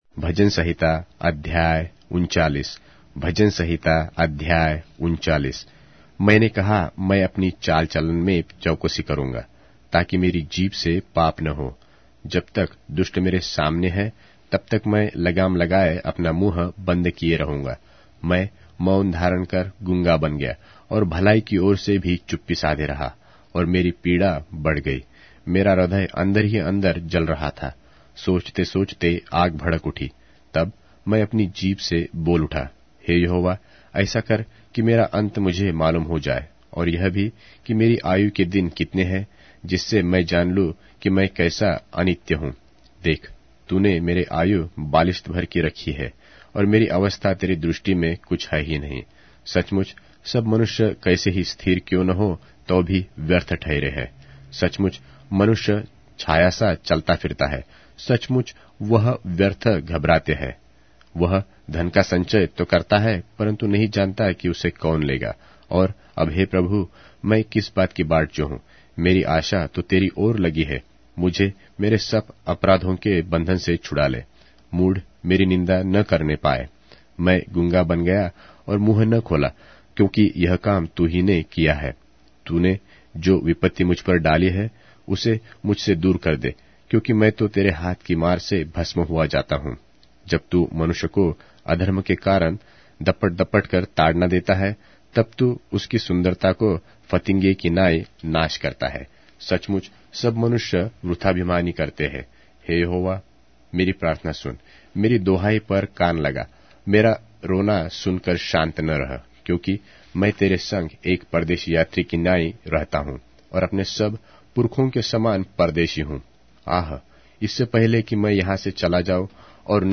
Hindi Audio Bible - Psalms 2 in Irvmr bible version